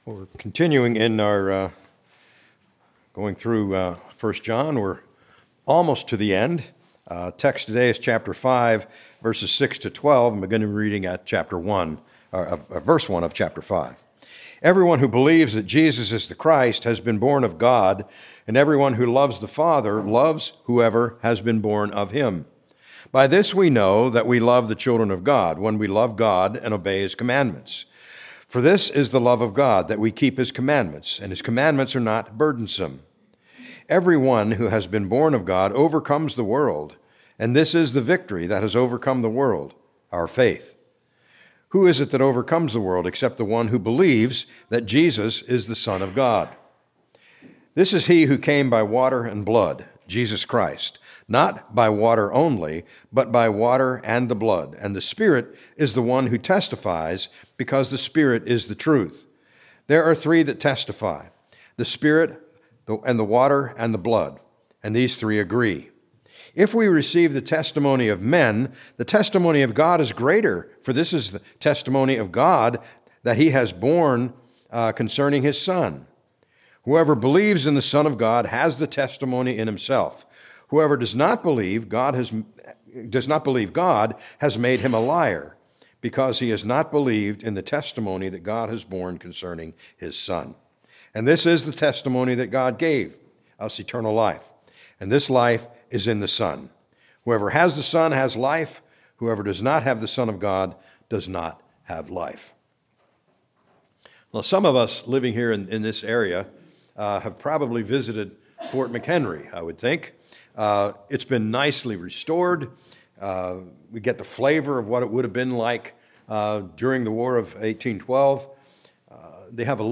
Message: "Water and Blood"